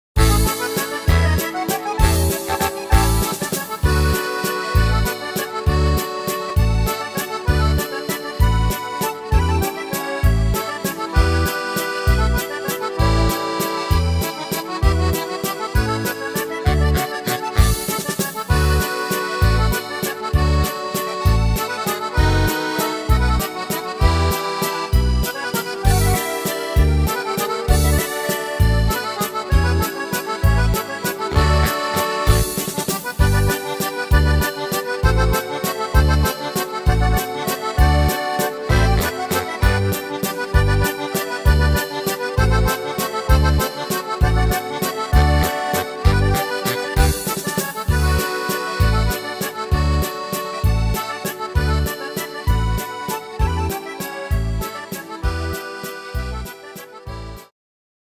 Tempo: 196 / Tonart: D-Dur